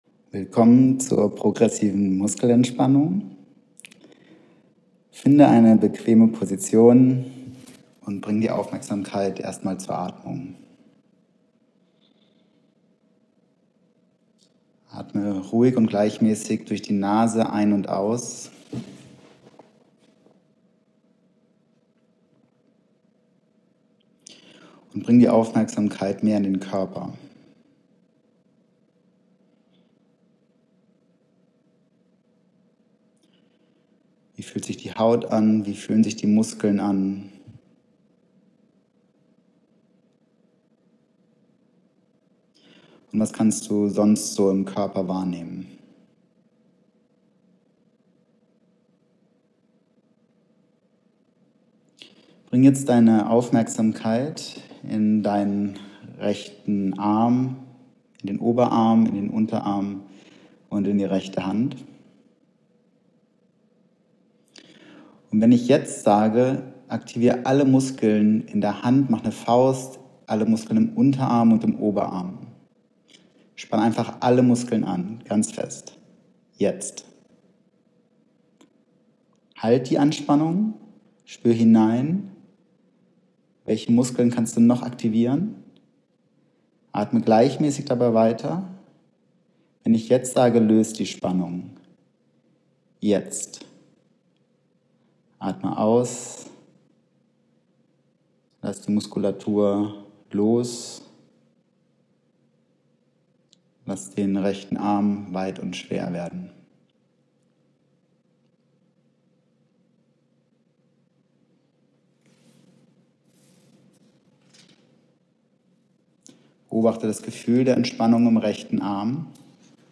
Anleitung zur Progressiven Muskelentspannung